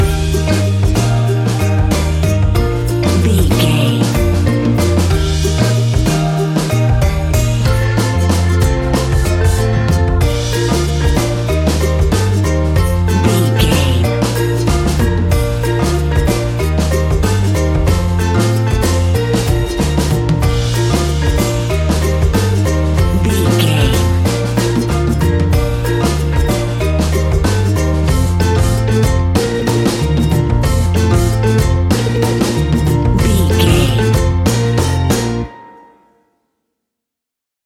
Ionian/Major
B♭
steelpan
drums
percussion
bass
brass
guitar